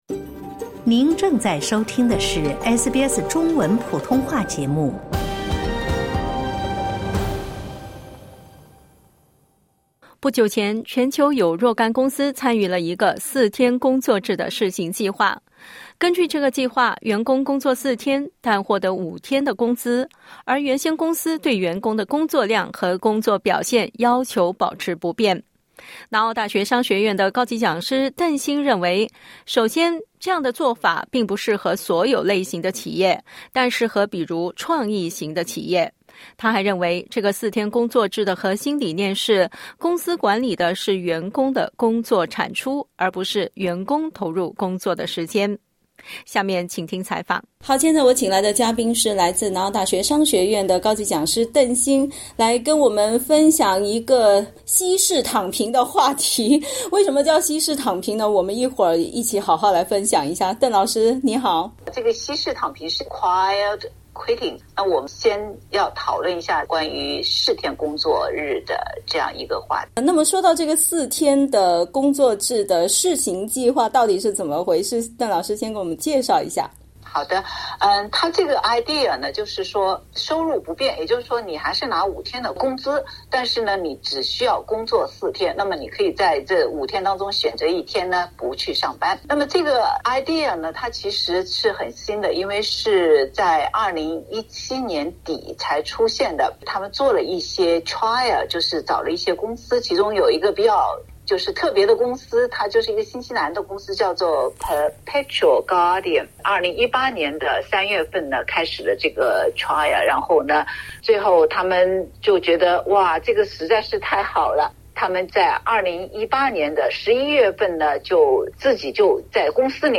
日前，全球有若干公司参与了一个四天工作制的试行计划。 （点击图片收听完整采访）